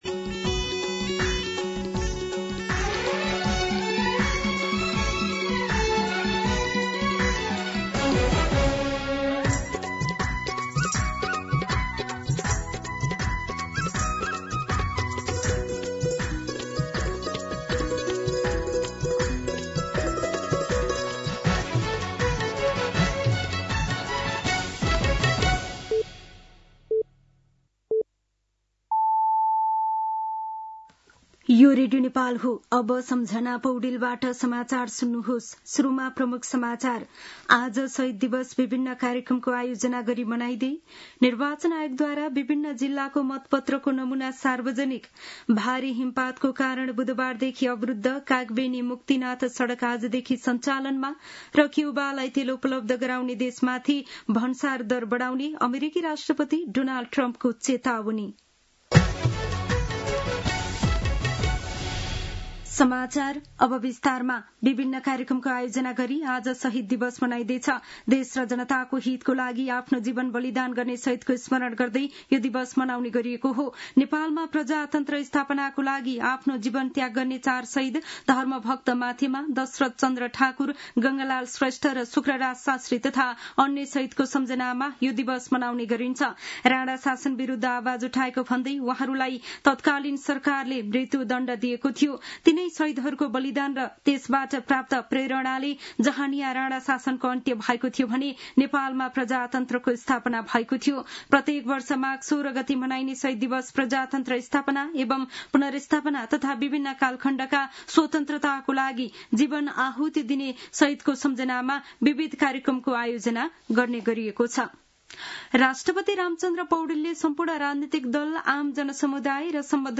दिउँसो ३ बजेको नेपाली समाचार : १६ माघ , २०८२